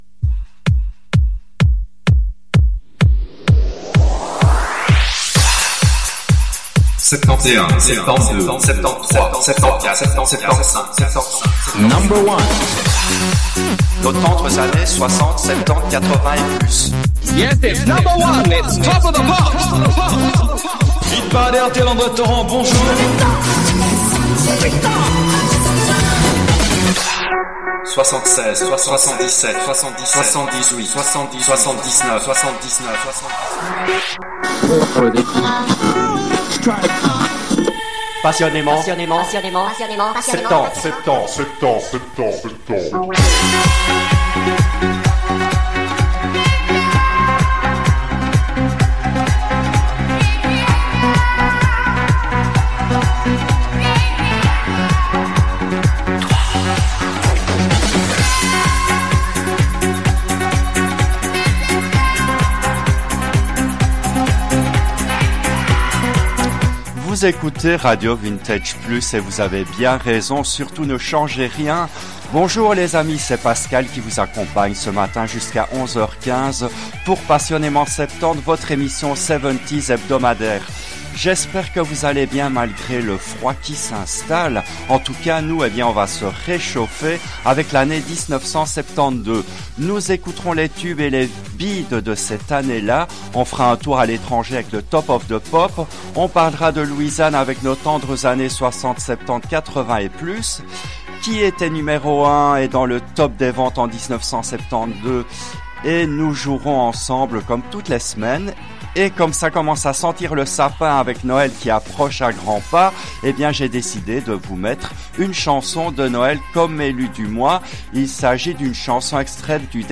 L’émission a été diffusée en direct le jeudi 05 décembre 2024 à 10h depuis les studios belges de RADIO RV+.